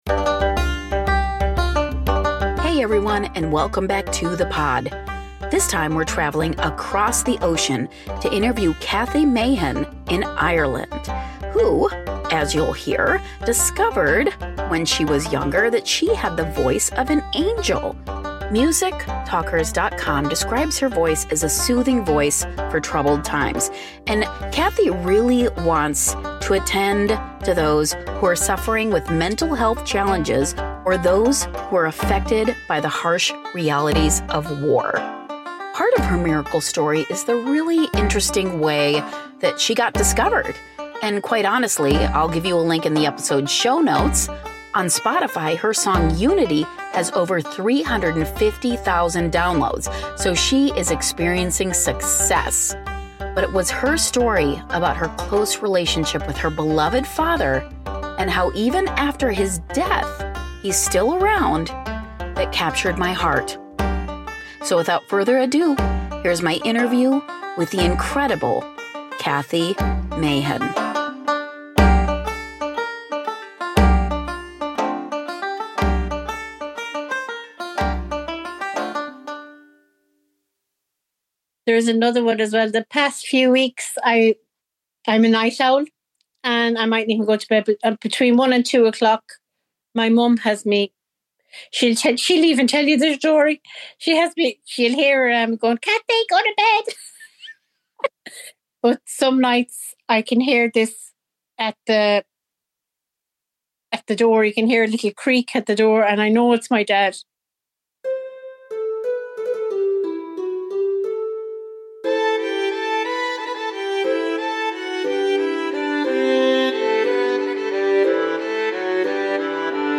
Byte Sized Blessings / Interview